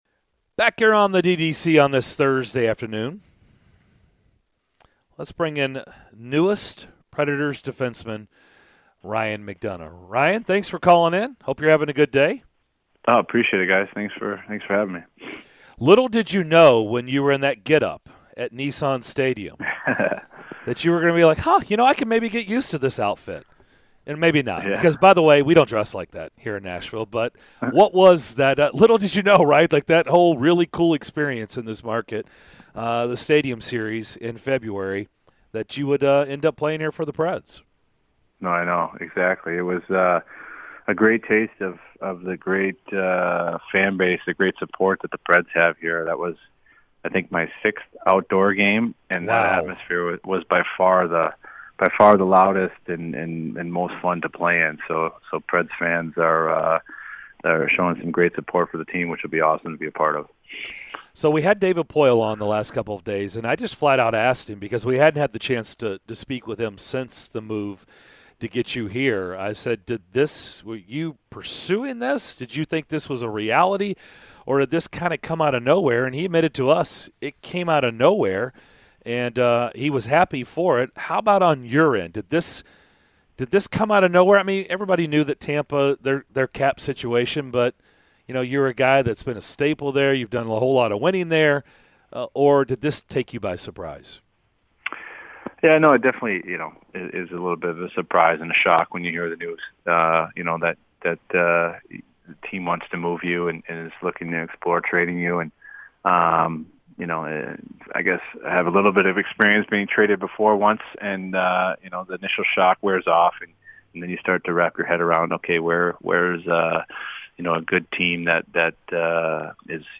Ryan McDonagh Full Interview (07-14-22)